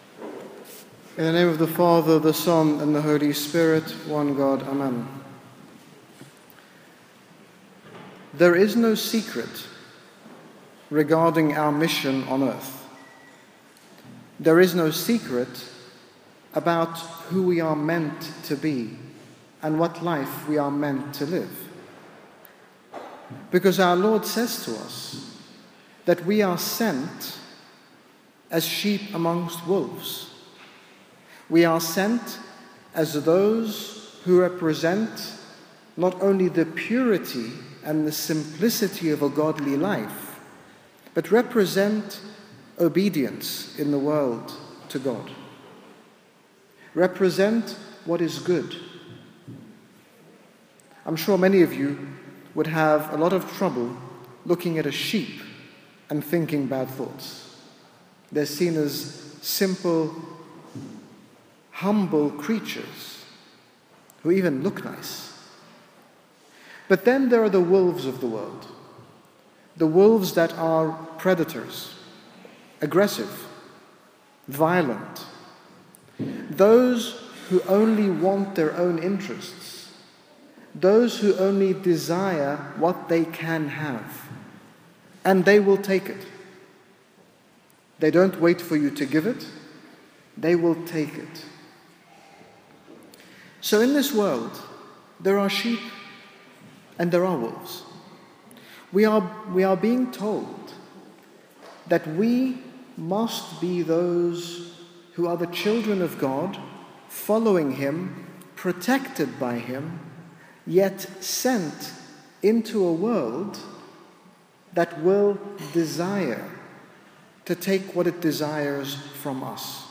In this sermon, given by His Grace Bishop Angaelos on the Feast of the Apostles, His Grace talks about the importance of being ready to hear the call from our Lord to 'follow Him'. His Grace uses the Apostles as a clear example of how our lives can be transformed by the Lord if we respond to His call.